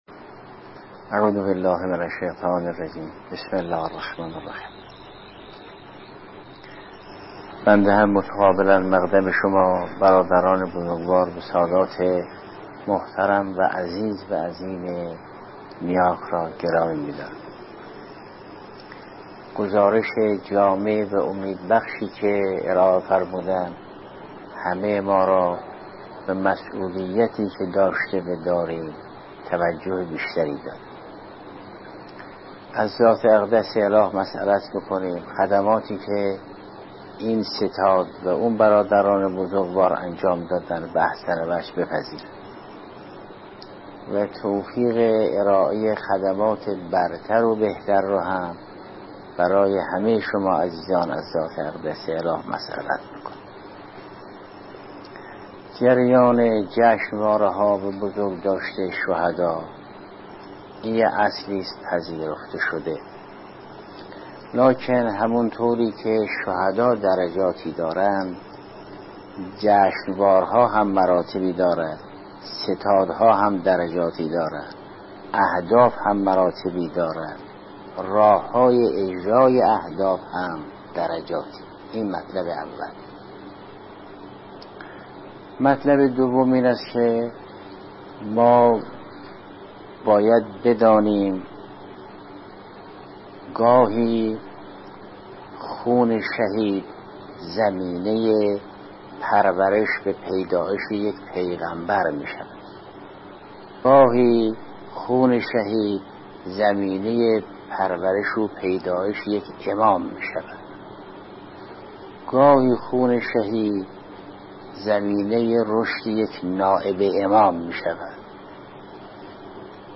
جربان-جشنواره-ها-آیت-الله-جوادی-آملی-درجمع-اعضای-ستاد-یادواره-شهدای-نیاک.mp3